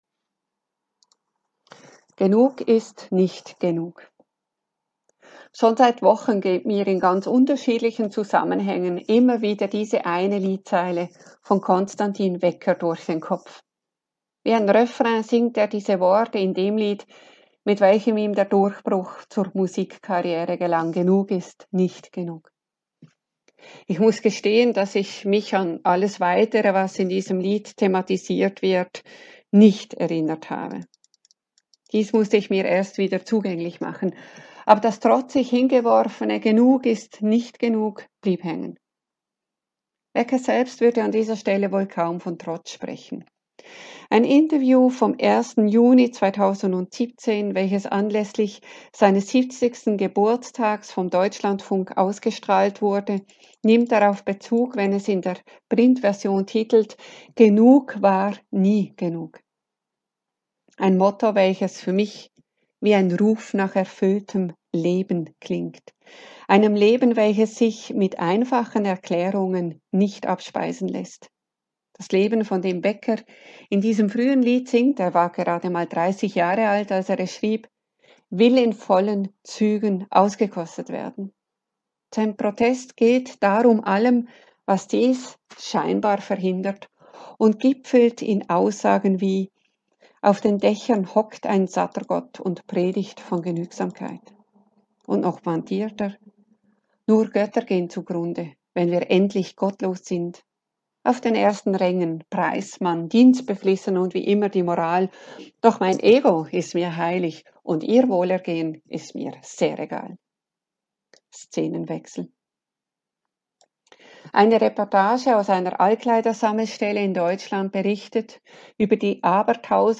Andacht